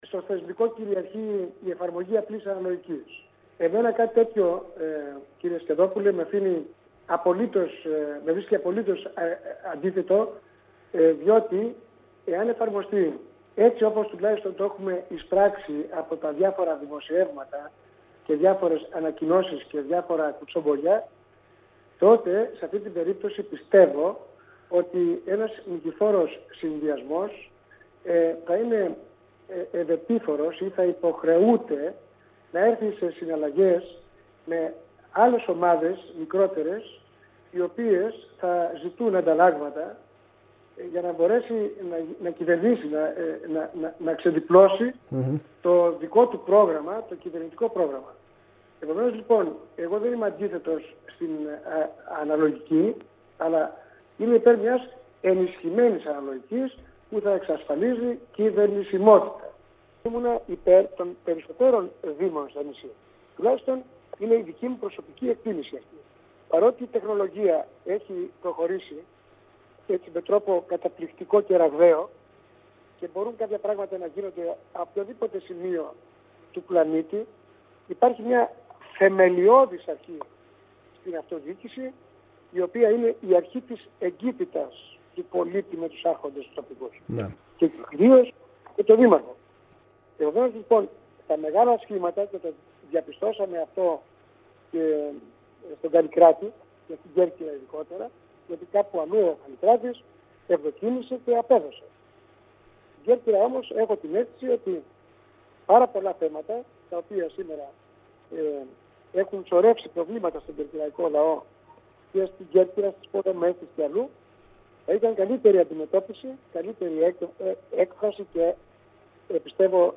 Ακούστε απόσπασμα των δηλώσεων του κ. Κροκίδη στον σύνδεσμο που ακολουθεί: